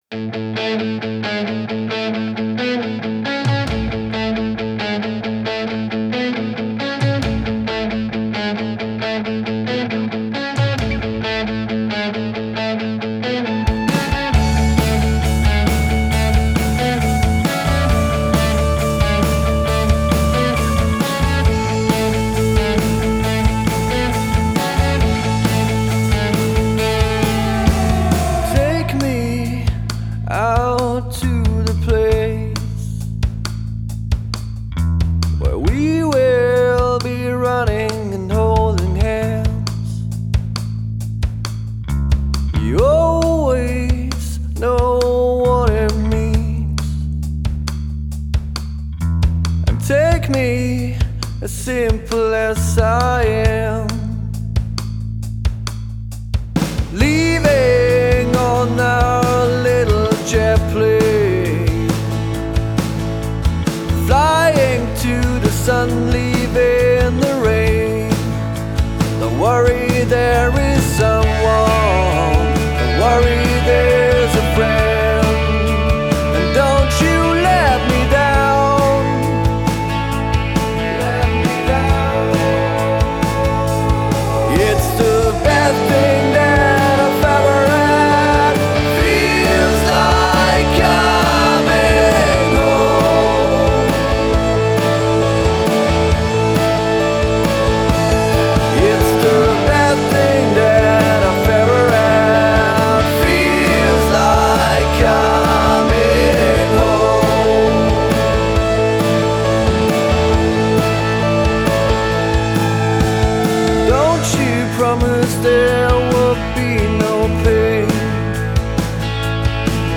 Rock n Roll aus Tirol
Wir haben auch ein paar eigene Songs im Programm.